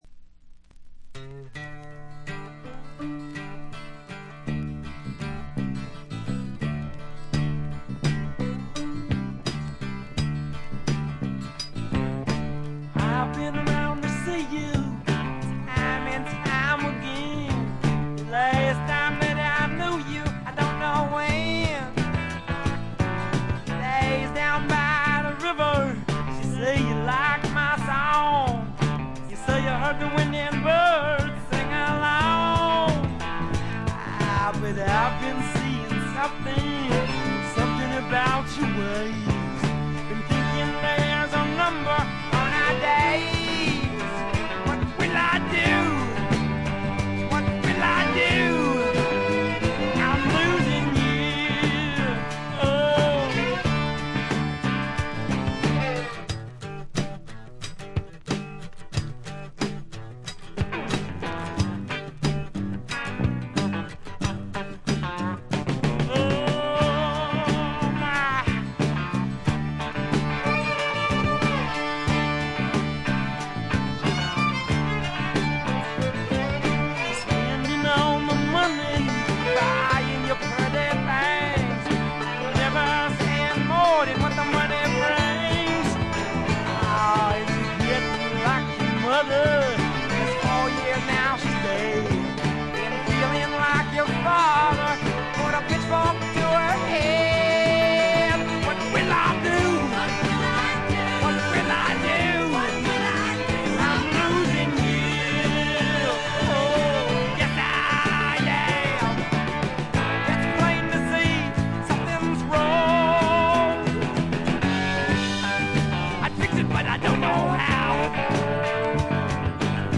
軽微なチリプチ少々、散発的なプツ音が少し。
まさしくスワンプロックの理想郷ですね。
試聴曲は現品からの取り込み音源です。
Vocals, Acoustic Guitar, Piano, Violin